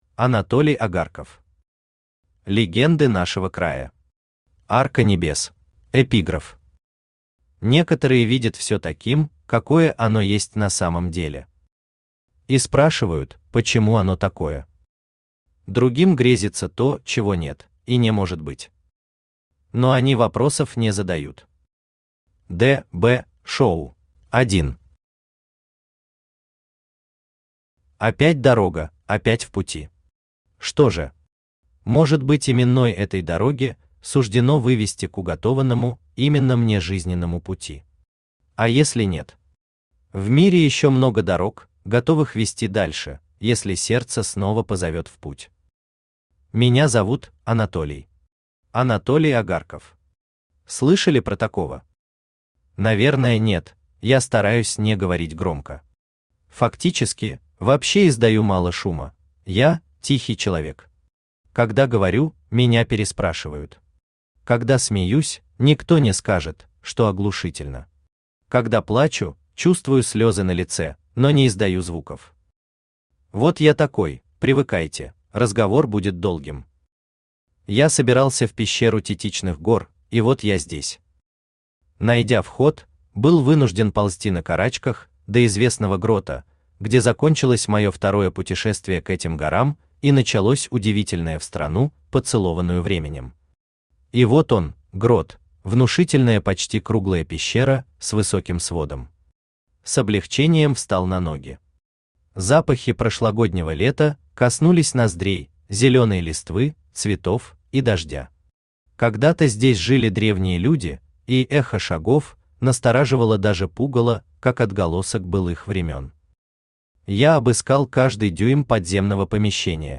Аудиокнига Легенды нашего края. Арка небес | Библиотека аудиокниг
Арка небес Автор Анатолий Агарков Читает аудиокнигу Авточтец ЛитРес.